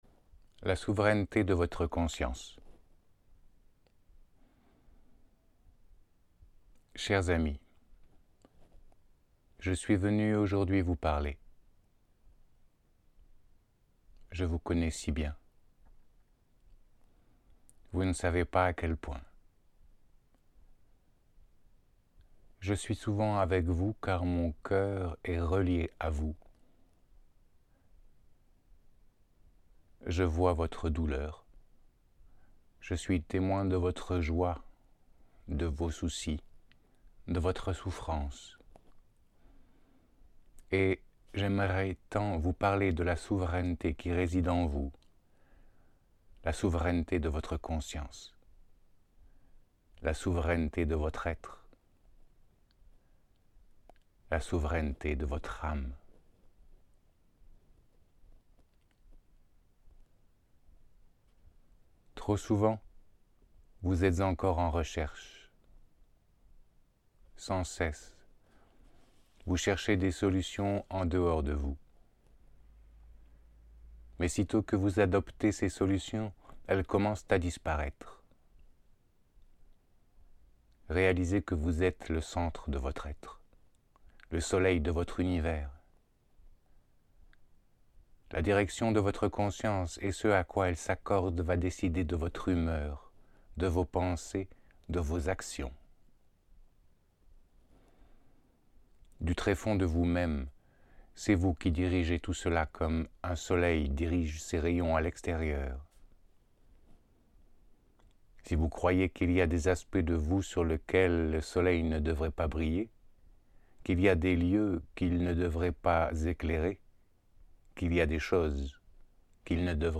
Réponses de Jeshua aux questions du public lors d’un channeling au centre spirituel du Cercle de Lune en Belgique